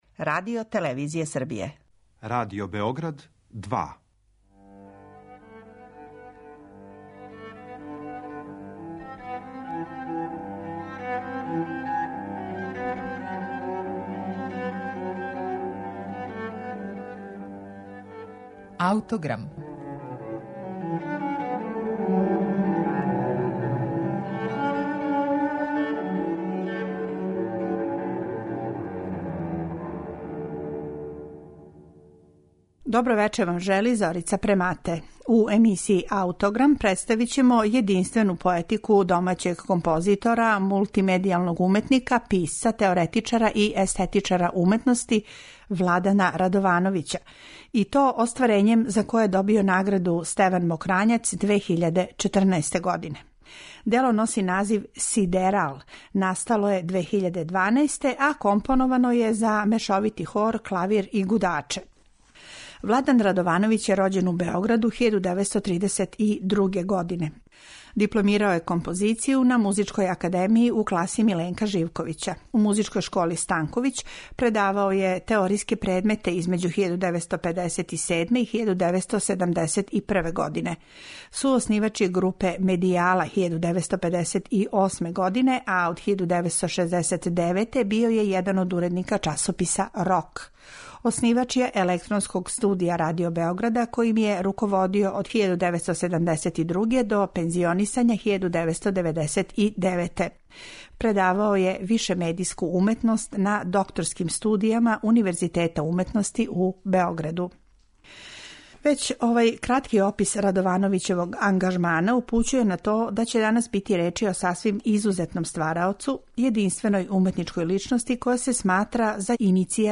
Емитоваћемоп снимак са премијерног извођења